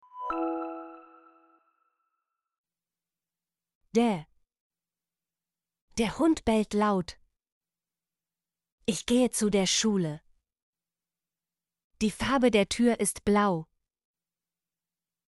der - Example Sentences & Pronunciation, German Frequency List